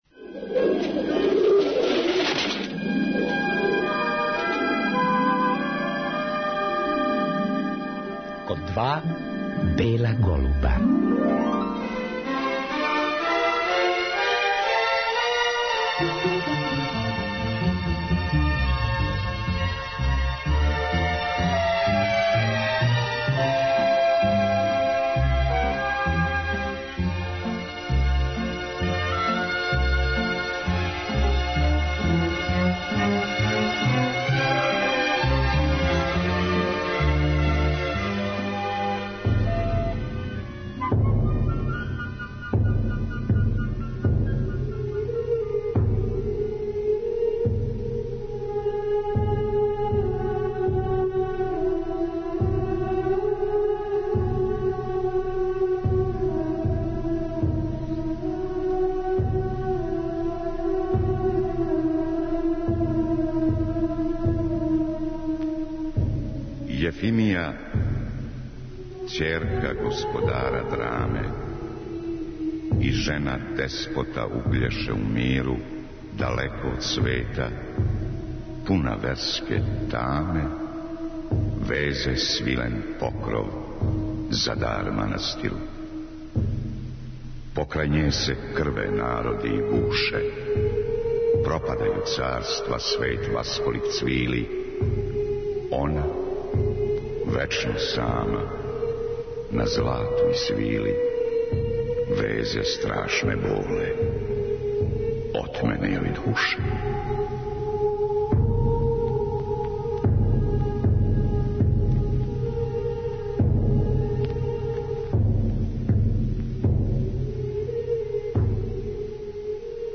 Чућемо и одломке из радио-драме "Јефимија" Звонимира Костића из 1986. године.